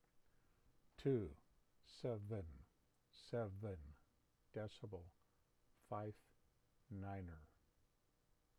The number 277.59 will be spoken as T00, SEV EN, SEV EN, DECIBEL, FIFE, NIN ER.